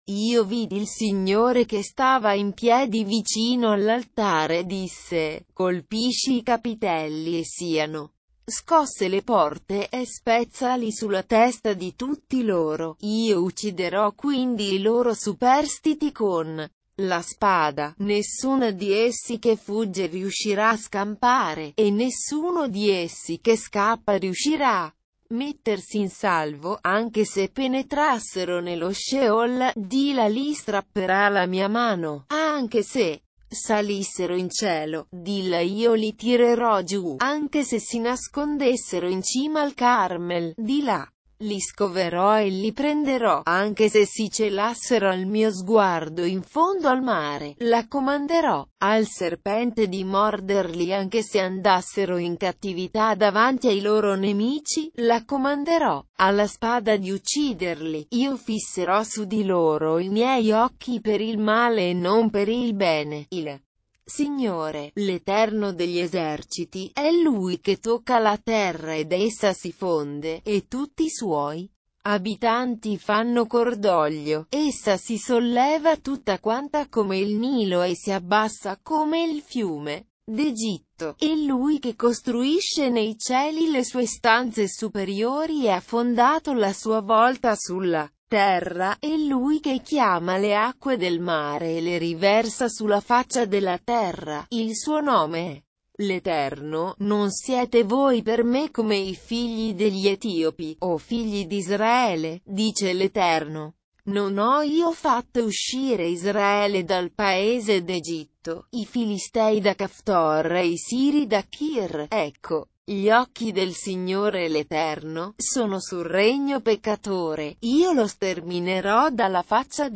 Leitura na versão LND - Italiano